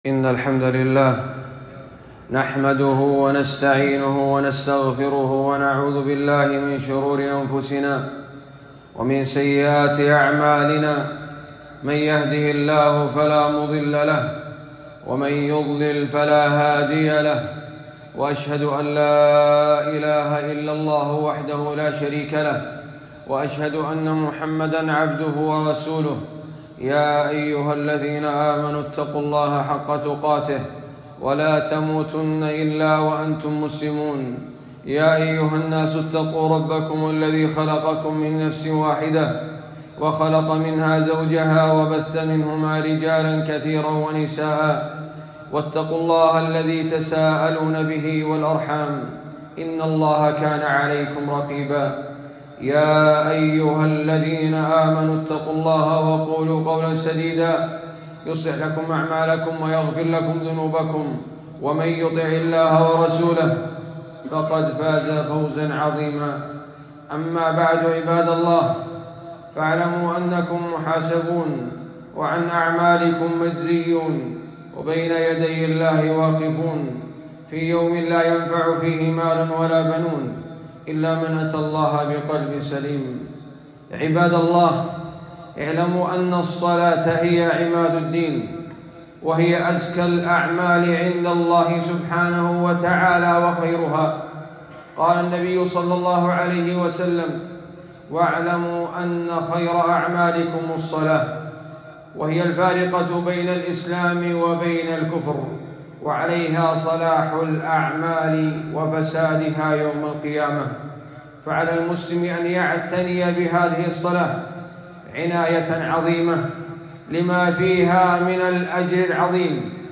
خطبة - ( خذوا زينتكم عند كل مسجد )